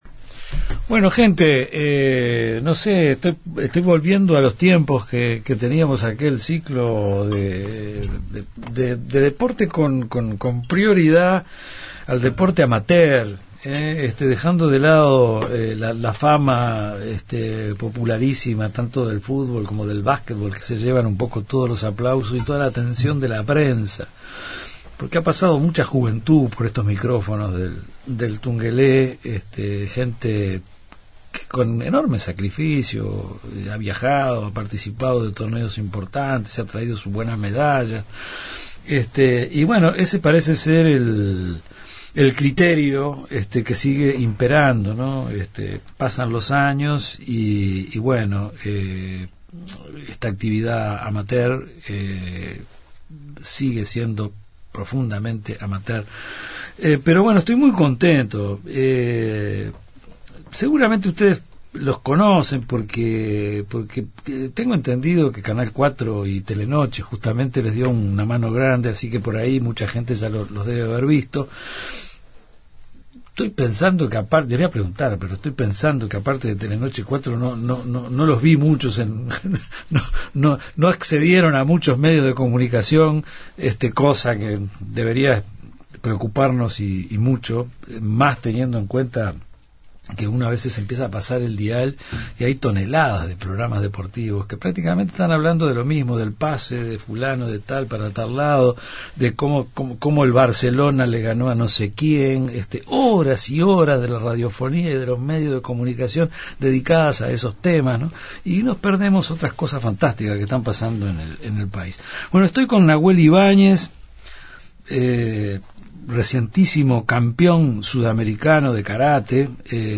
Recibimos a los 3 participantes que representaron a Uruguay en el sudamericano